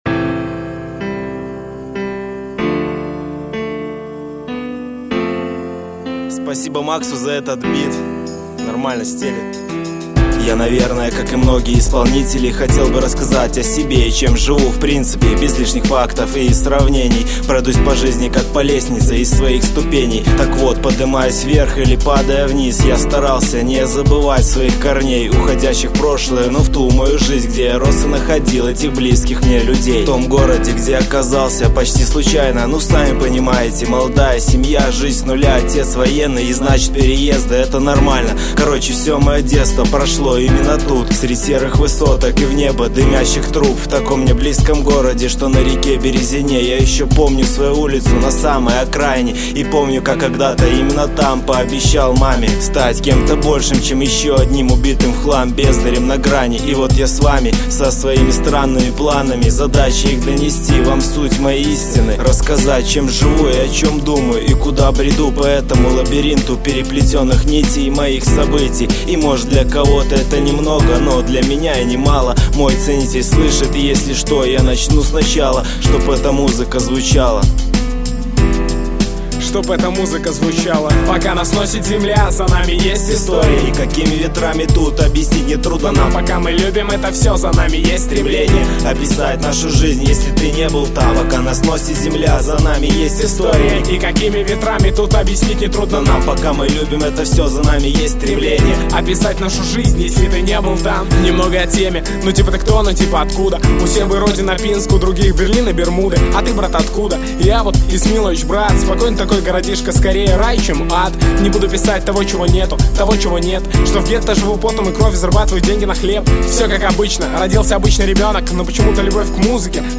похож голосом и манерой на Гуфа